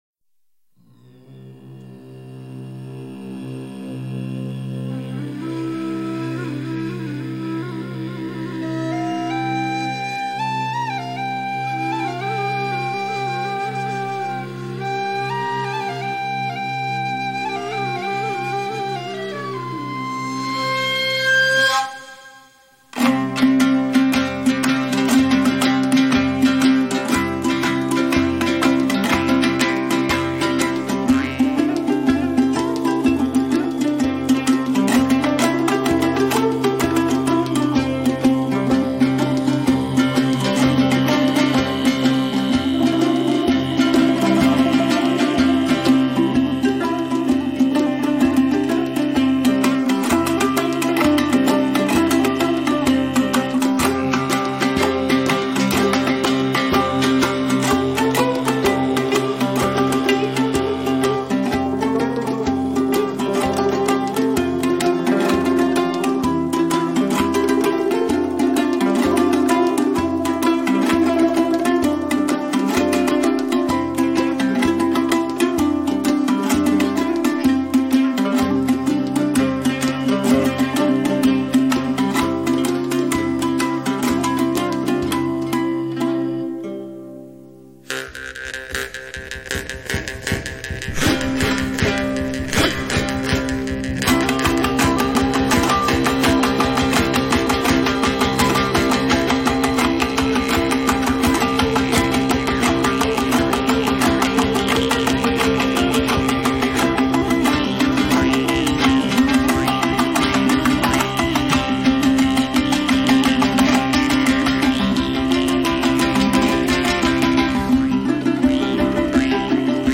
ұлт аспаптар ансамбльдеріне арналған шығармалары